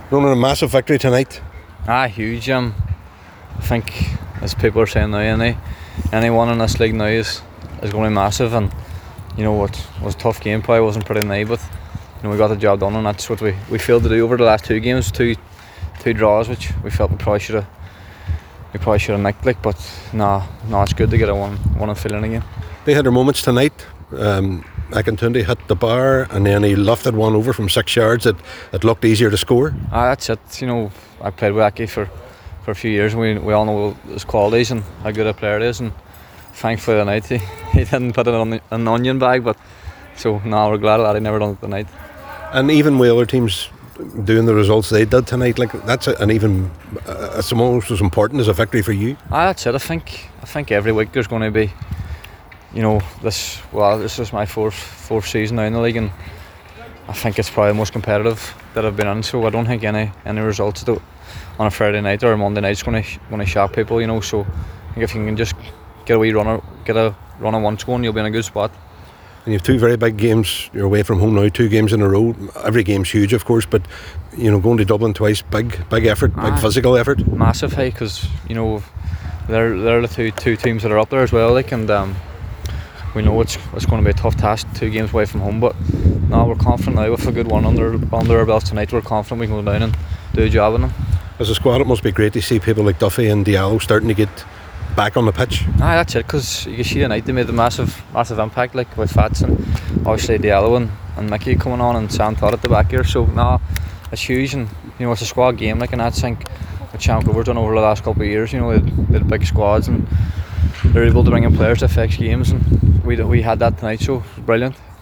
Reaction: Derry City players on their win over Bohemians